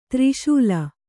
♪ tri śula